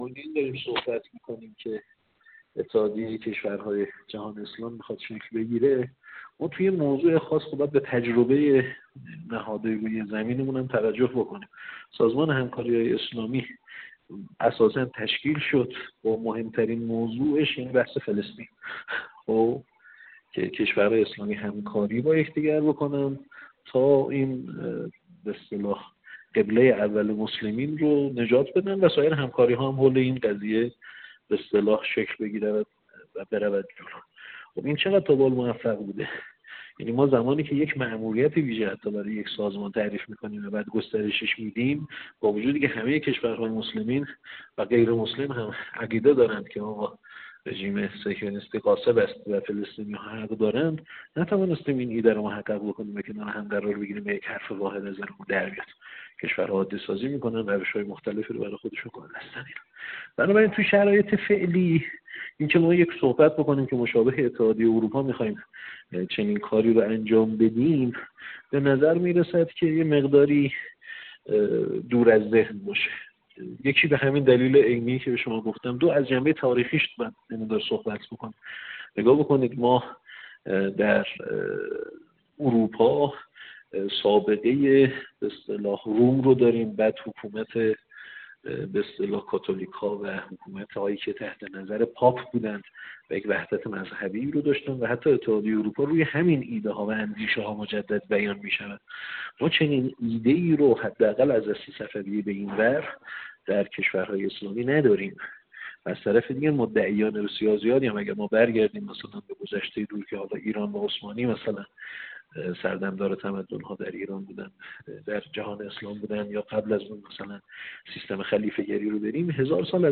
کارشناس مسائل اقتصادی در گفت‌وگو با ایکنا